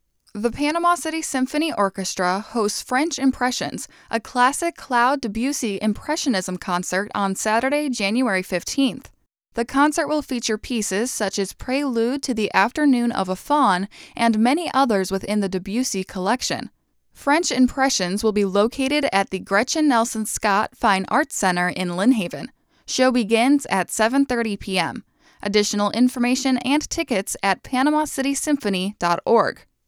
PC Symphony Orchestra French Impressions PSA